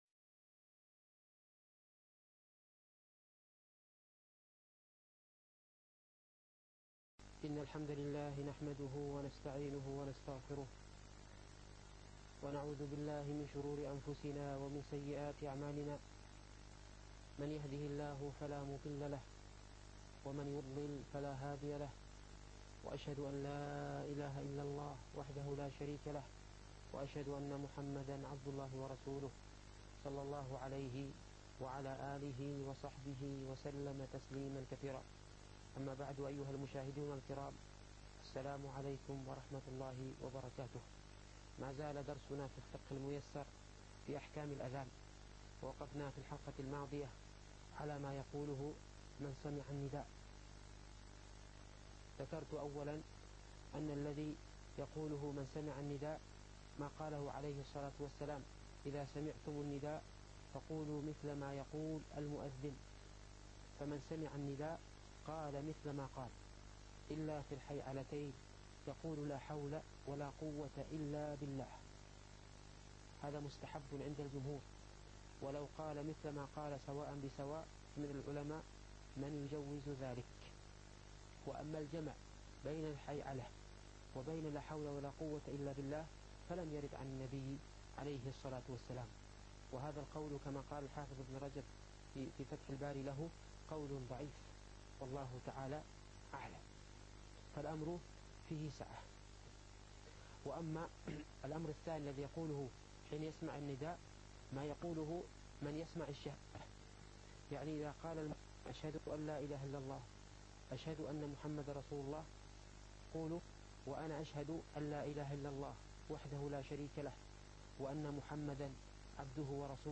الفقه الميسر - الدرس الثاني عشر